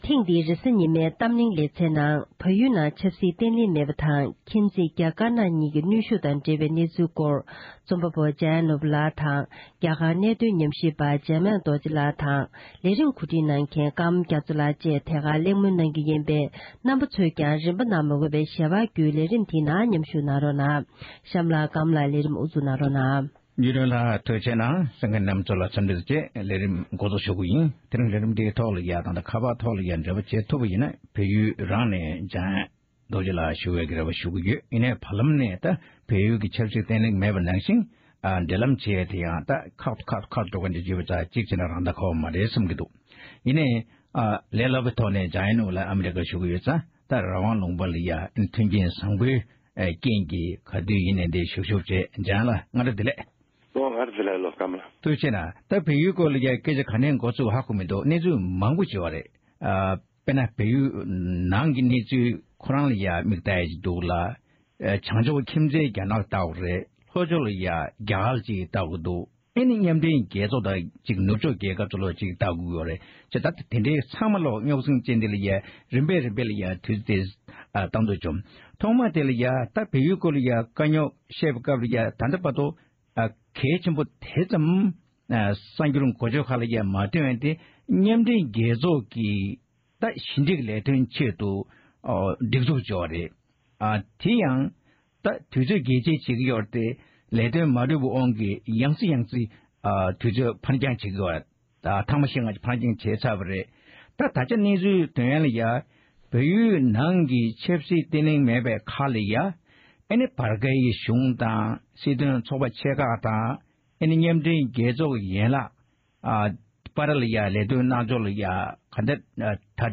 ༄༅༎དེ་རིང་གི་རེས་གཟའ་ཉི་མའི་གཏམ་གླེང་གི་ལེ་ཚན་ནང་།